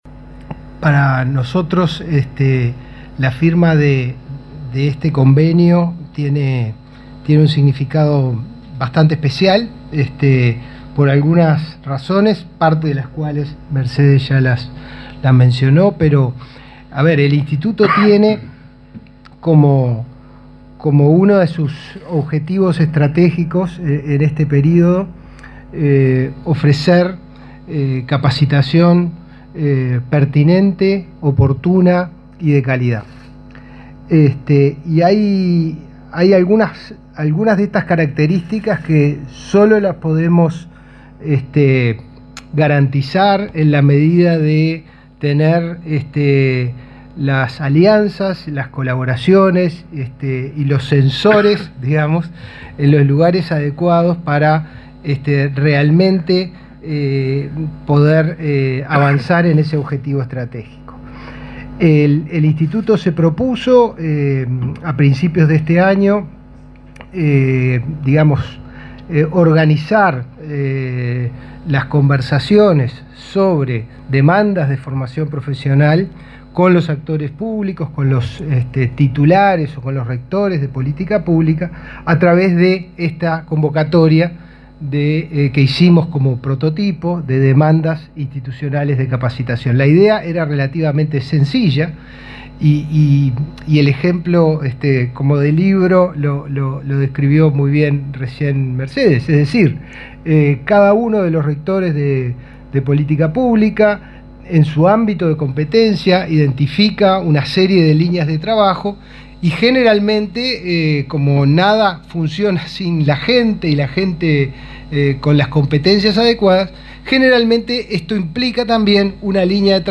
Palabras del director de Inefop y el ministro de Ganadería